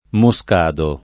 moscado [ mo S k # do ]